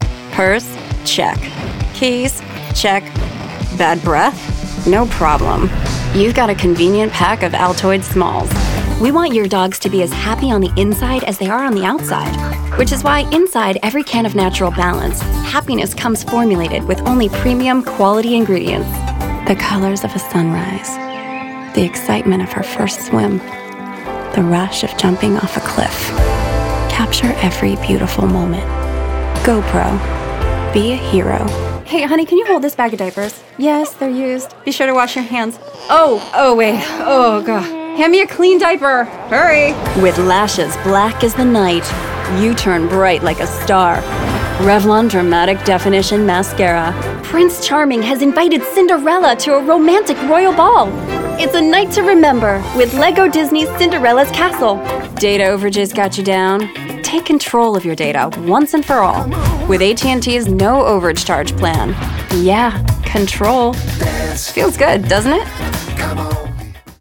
Promos